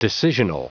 Prononciation du mot decisional en anglais (fichier audio)
Prononciation du mot : decisional